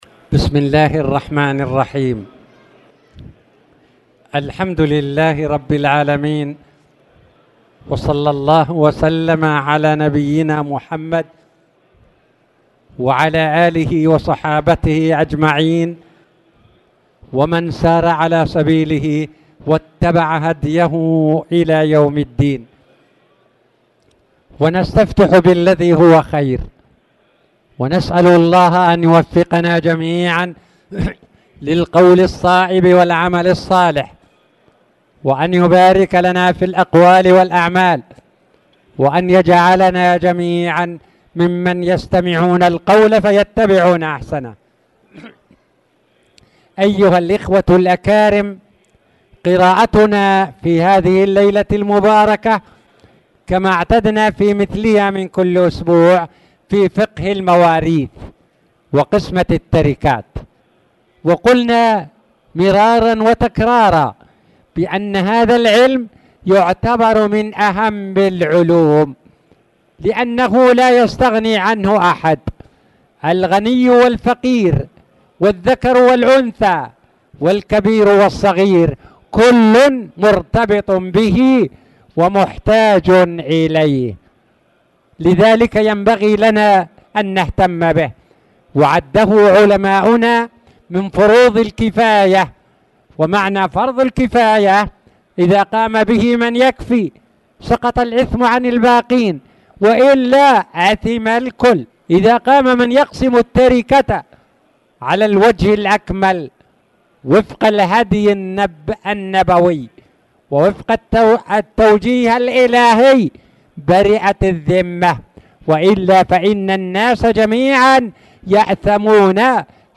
تاريخ النشر ٢٨ جمادى الآخرة ١٤٣٨ هـ المكان: المسجد الحرام الشيخ